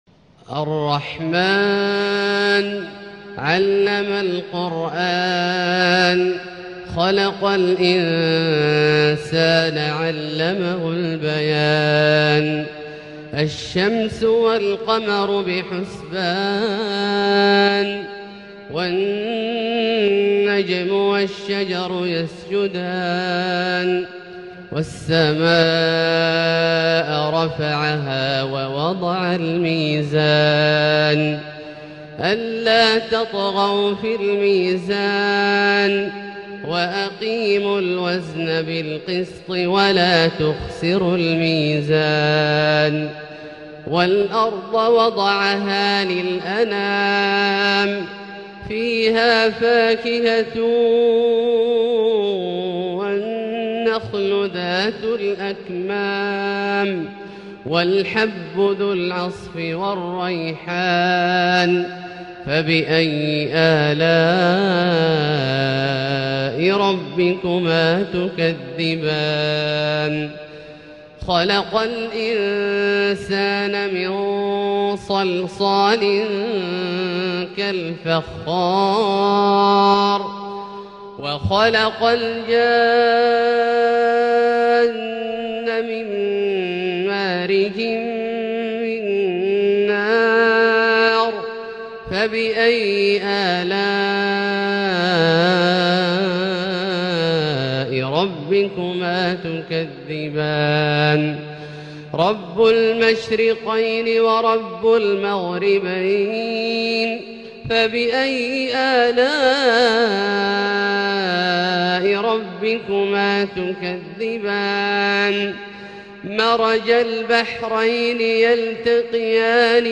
تلاوة فاقت حدود الوصف لـسورة الرحمن كاملة للشيخ د. عبدالله الجهني من المسجد الحرام | Surat Ar-Rahman > تصوير مرئي للسور الكاملة من المسجد الحرام 🕋 > المزيد - تلاوات عبدالله الجهني